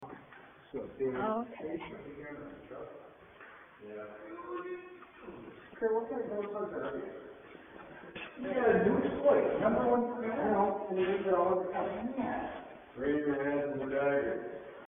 In the basement of one of the buildings, two other investigators are talking to each other but another voice yells at them.
olddormsbasement4.mp3